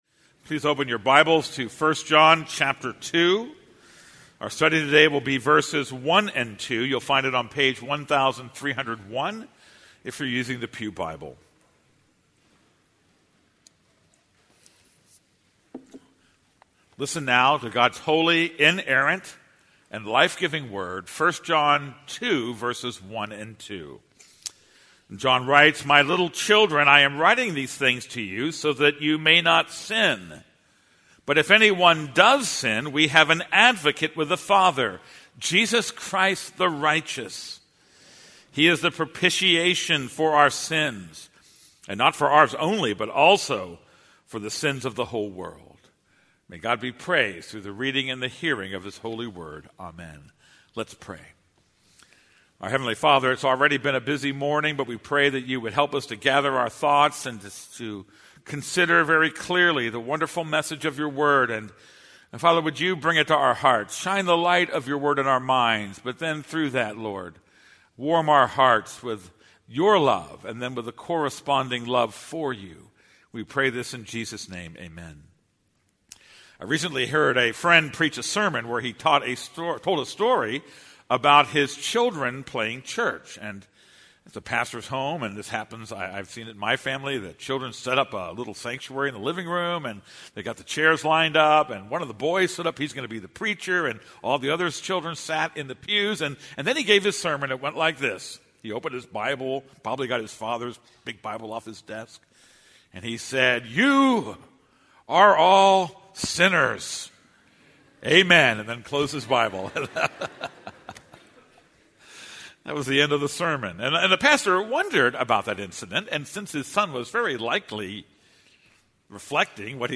This is a sermon on 1 John 2:1-2.